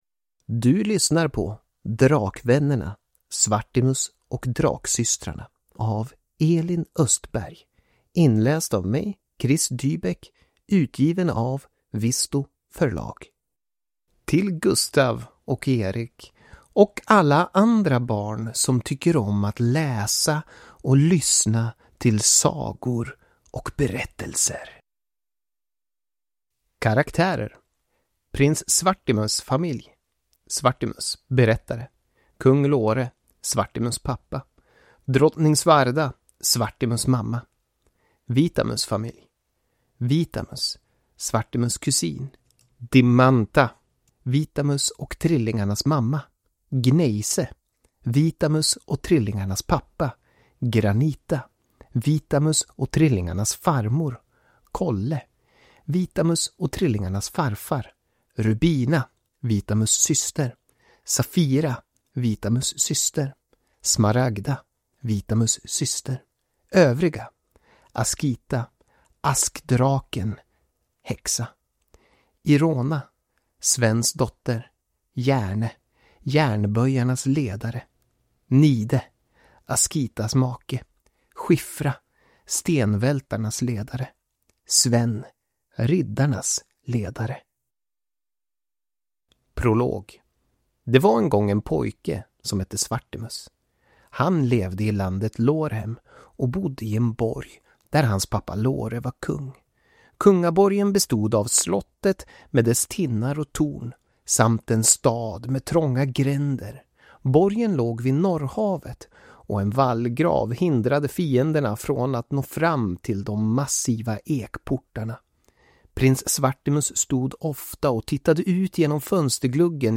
Svartimus och Draksystrarna (ljudbok) av Elin Östberg